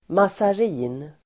Ladda ner uttalet
mazarin substantiv, mazarin bun Uttal: [masar'i:n] Böjningar: mazarinen, mazariner Definition: bakverk av mördeg (med fyllning av mandel, ägg och socker) (rich pastry shell (with a filling of almonds, eggs, and sugar))